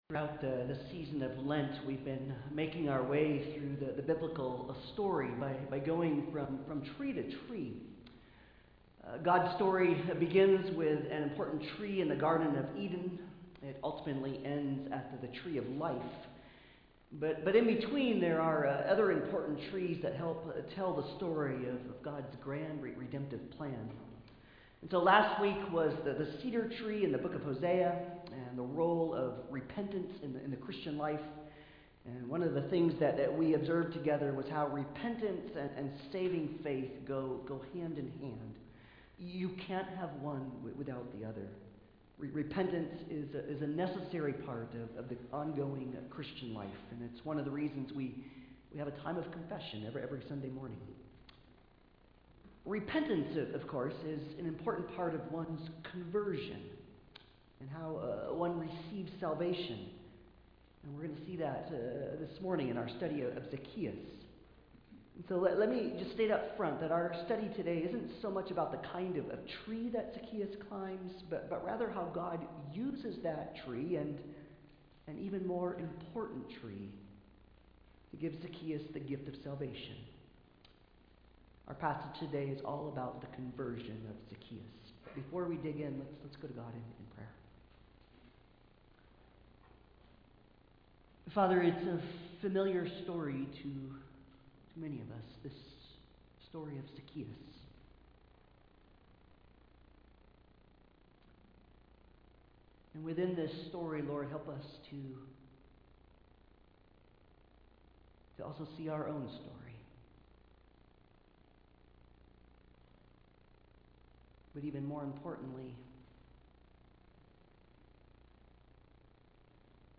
Luke 19:1-10 Service Type: Sunday Service « Like a Cedar of Lebanon The Kingdom of Heaven is Like a Mustard Seed…